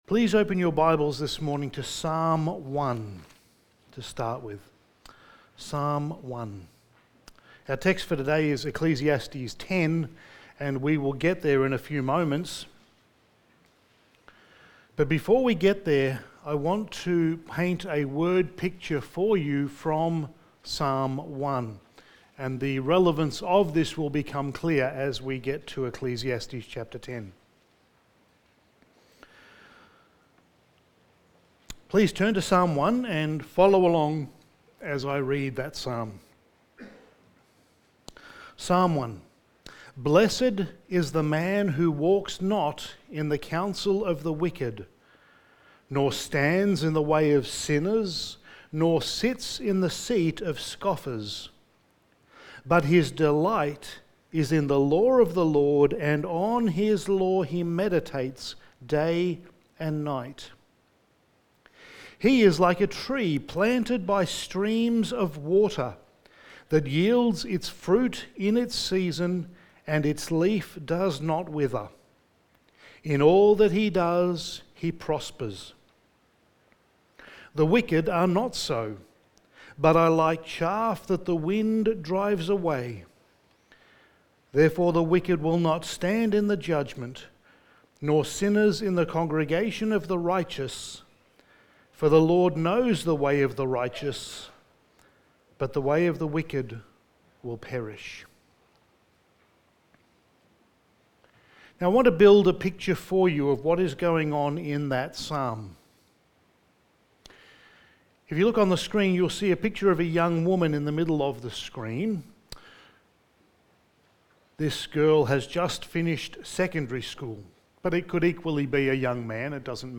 Passage: Ecclesiastes 10:1-11 Service Type: Sunday Morning